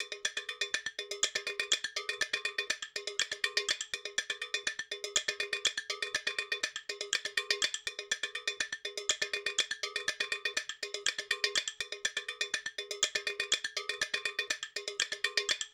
Cowbell.wav